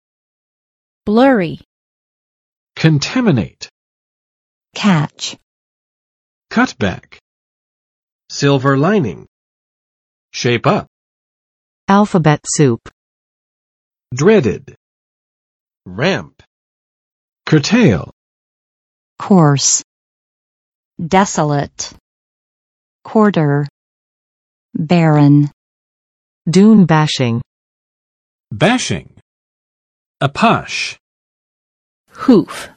[ˋblɝɪ] adj. 模糊的
blurry.mp3